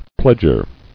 [pledg·er]